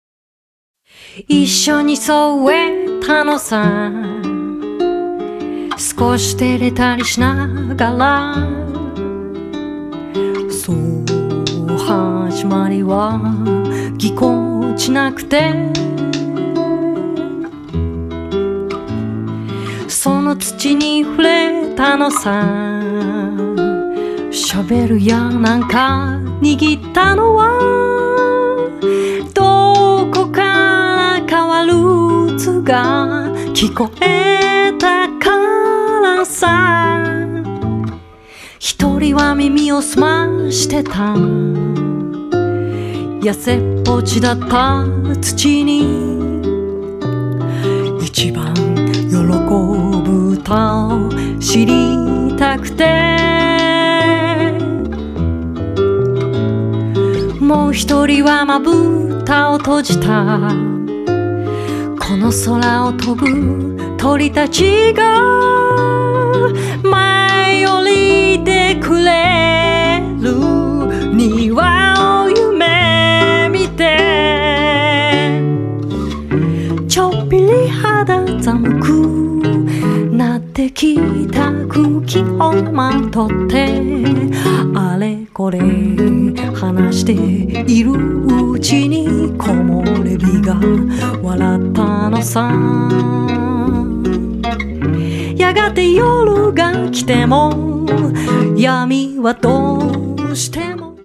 ドラム
ベース